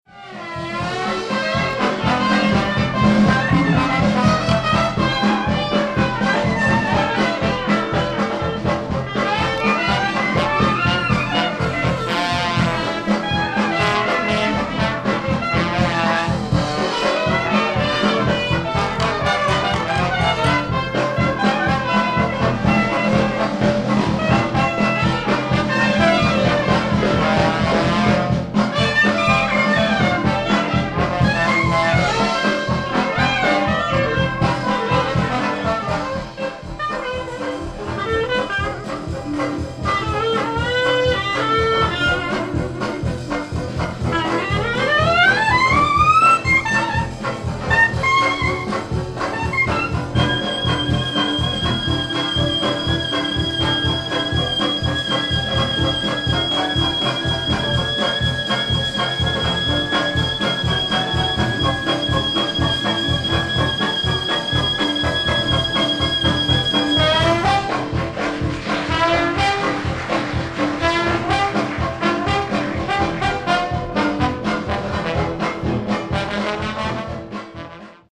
Performing at Dixieland Hall